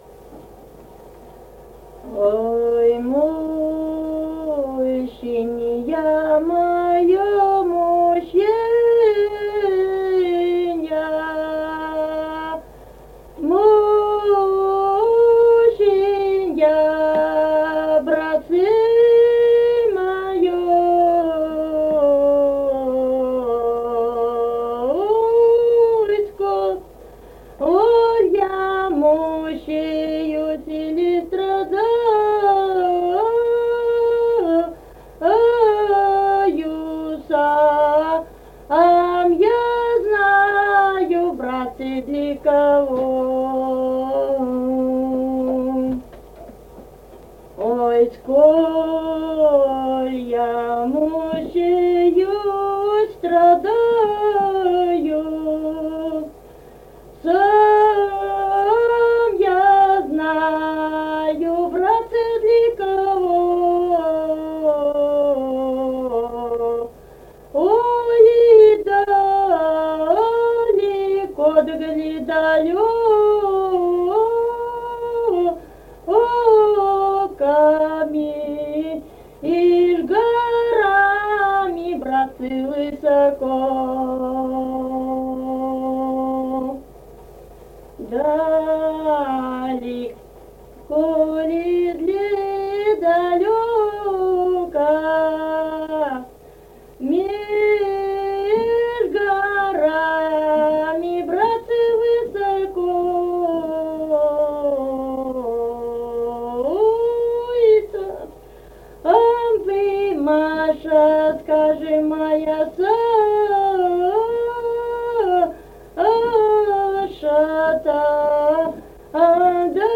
Русские песни Алтайского Беловодья 2 «Ой, мученье моё, мученье», лирическая.
Республика Казахстан, Восточно-Казахстанская обл., Катон-Карагайский р-н, с. Белое, июль 1978.